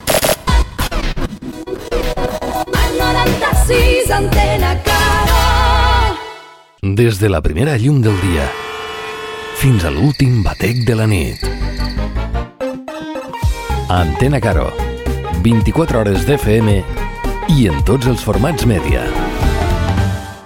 Enregistrament extret del programa "Les Veus dels Pobles" de Ràdio Arrels.